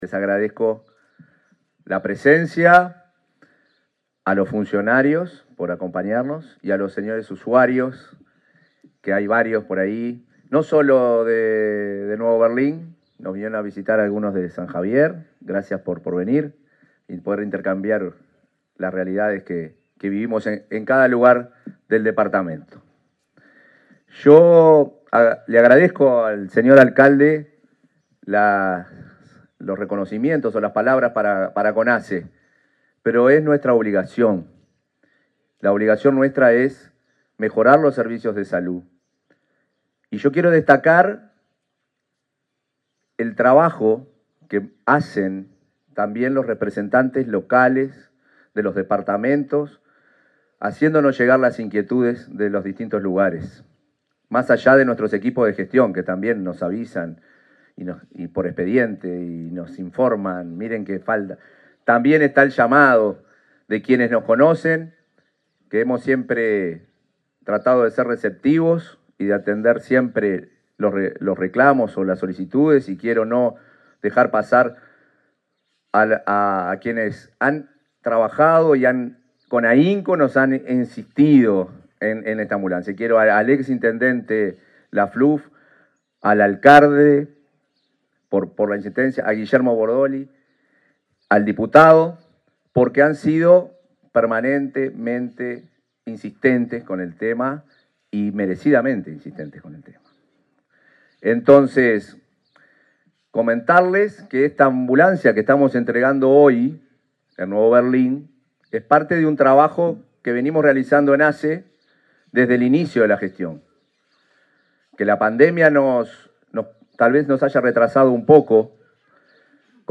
Palabras del presidente de ASSE, Marcelo Sosa, en Nuevo Berlín
Palabras del presidente de ASSE, Marcelo Sosa, en Nuevo Berlín 17/10/2024 Compartir Facebook X Copiar enlace WhatsApp LinkedIn La Administración de los Servicios de Salud del Estado (ASSE) entregó, este 17 de octubre, una ambulancia a la policlínica de salud de la localidad de Nuevo Berlín, en el departamento de Río Negro. En el evento, disertó el presidente de ASSE, Marcelo Sosa.